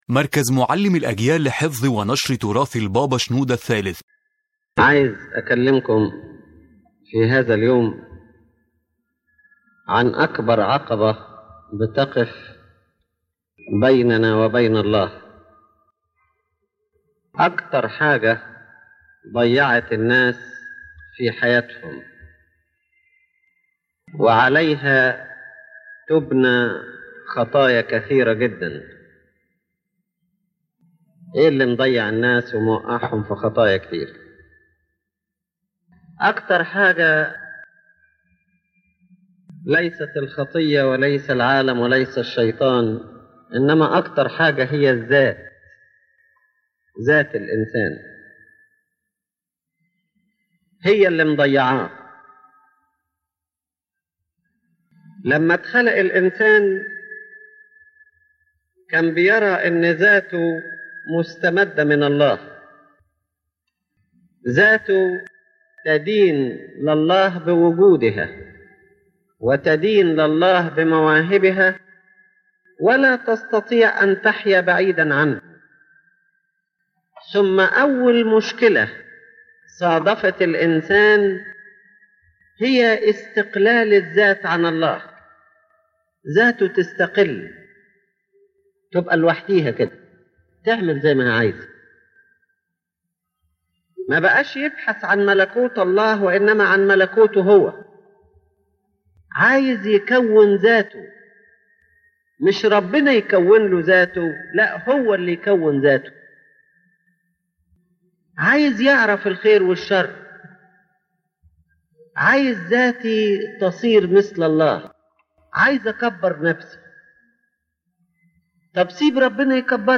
The lecture explains that the greatest obstacle between الإنسان and God is the “self” or the word “I”, as it is the main cause of الإنسان falling into sins and hindering his spiritual life.